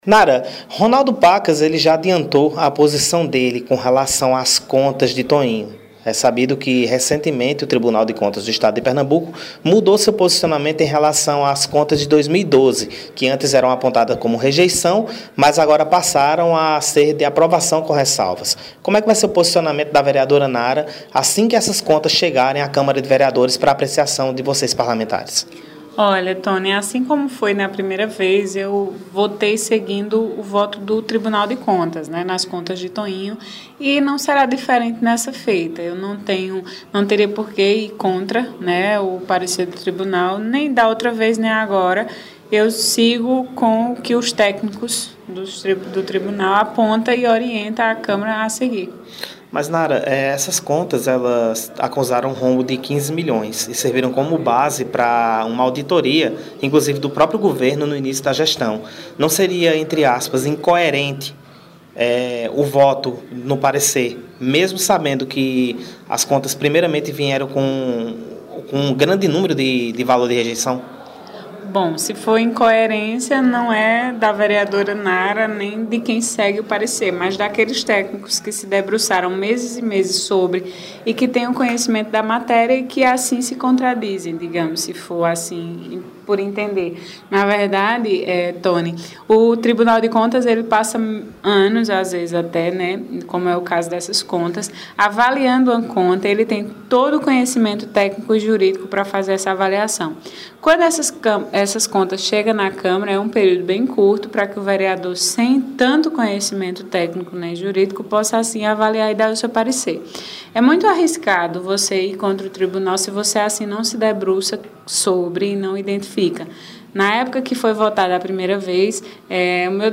Em entrevista concedida na redação do Blog, a vereadora situacionista Narah Leandro (PSB) também adiantou seu voto sobre as contas, do exercício 2012, do ex-prefeito de Santa Cruz do Capibaribe, Toinho do Pará (PHS).